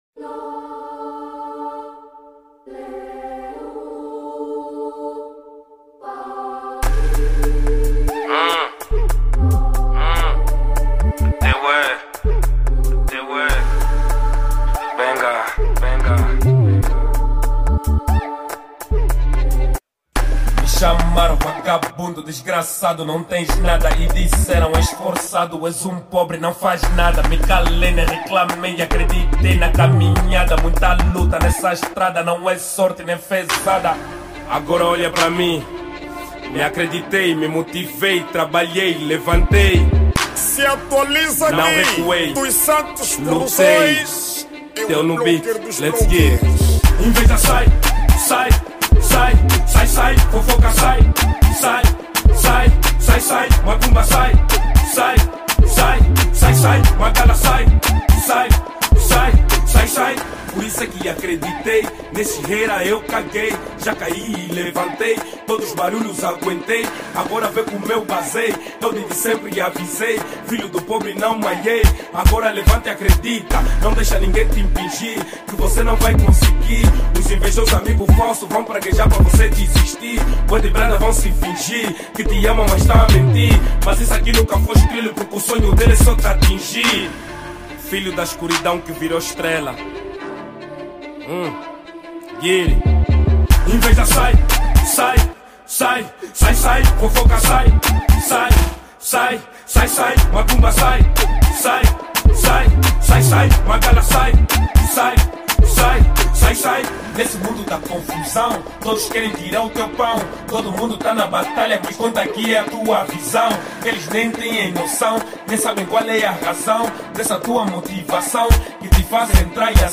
Categoria:  Afro Drill